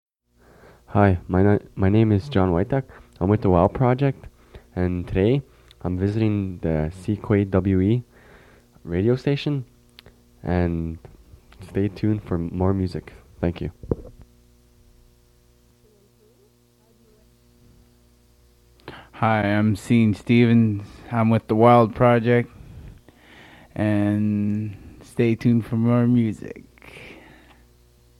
Radio jingles